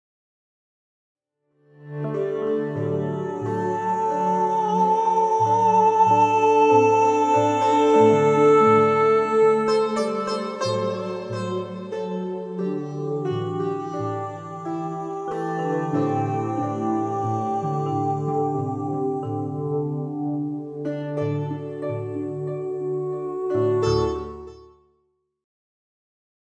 Meditative
Momentum-Aufnahmen